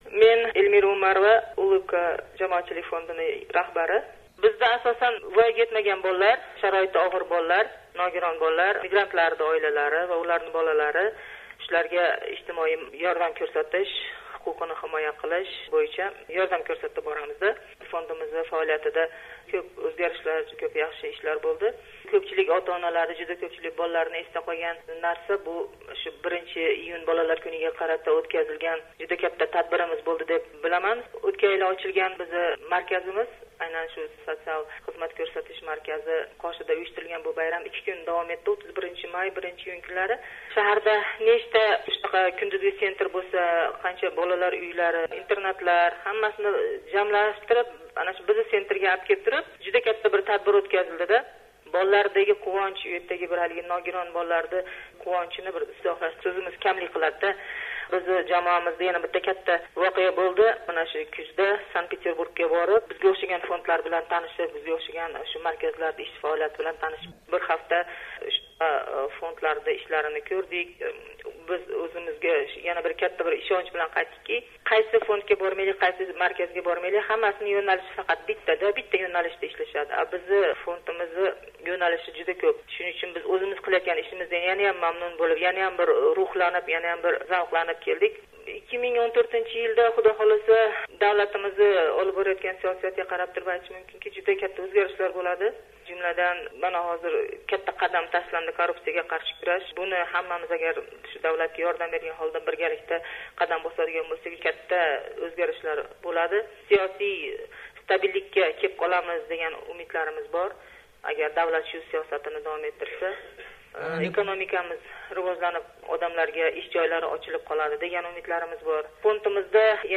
"Amerika Ovozi” O’shdagi nohukumat tashkilotlar va mahalliy ekspertlar fikrini so’radi.